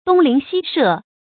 東鄰西舍 注音： ㄉㄨㄙ ㄌㄧㄣˊ ㄒㄧ ㄕㄜˋ 讀音讀法： 意思解釋： 住在左右前后的街坊鄰居。